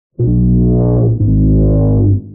Foghorn Kinda Gross
Y'know that spongebob sound effect where it goes like Brrr Mmmm whenever they showed a disgusting food item? Yeah, this is a bad recreation.
foghorn_kinda_gross.mp3